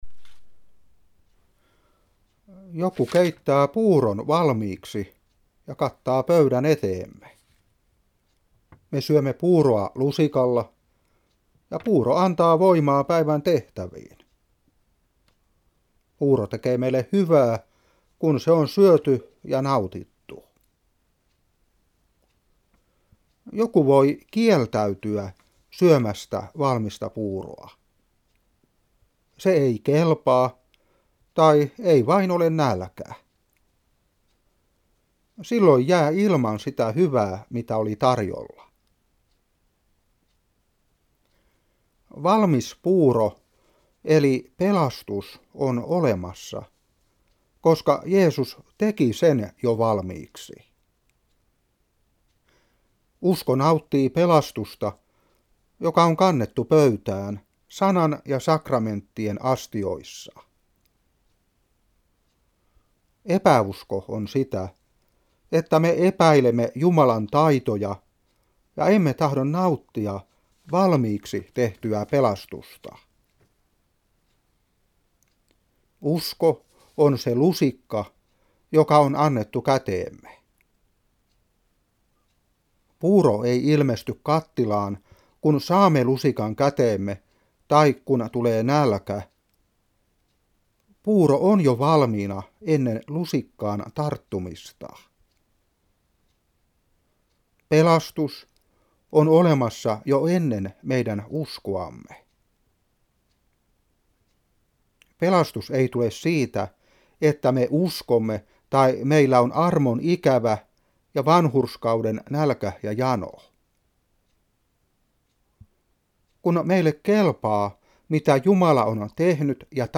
Seurapuhe 2009-10.